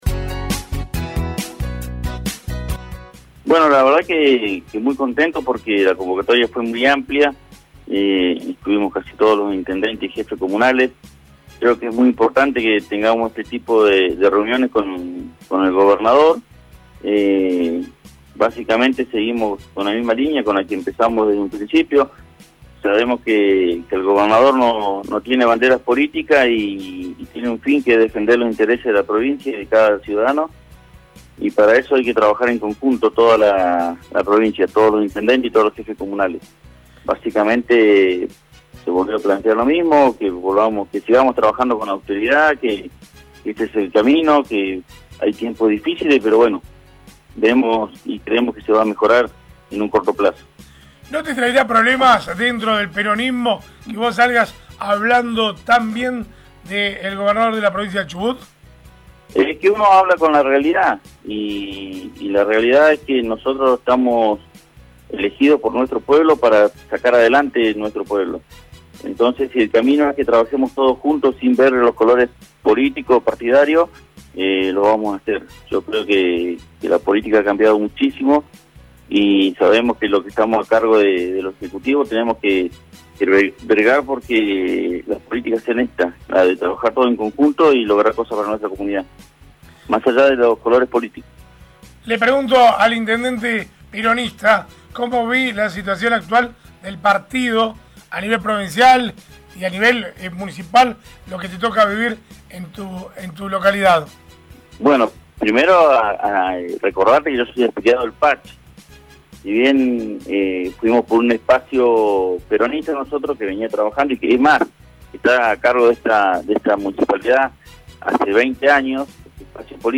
Uno de los presentes fue el intendente de Río Mayo, Gustavo Loyaute, quien le comentó a RADIOVISIÓN, qué acordaron con el gobernador: